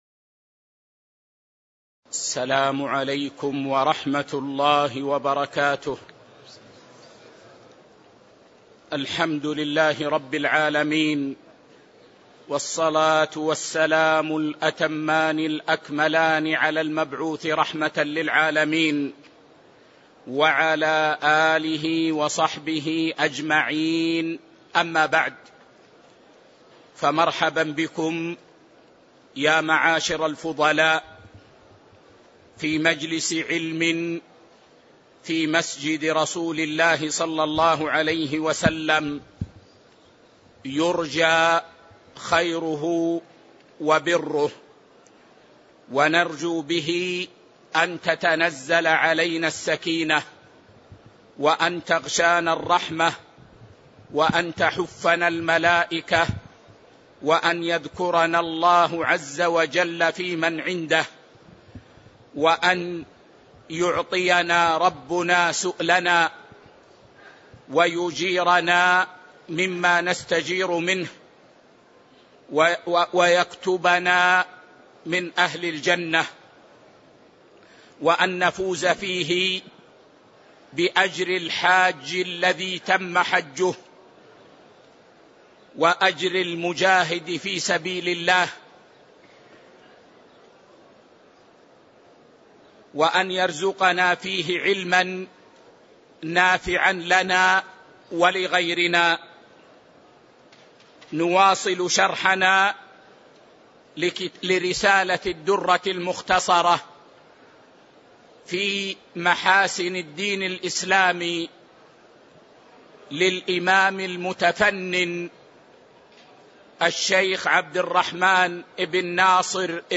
تاريخ النشر ١٧ شعبان ١٤٤٤ المكان: المسجد النبوي الشيخ